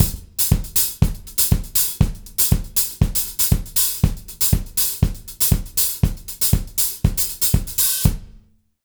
120ZOUK 06-R.wav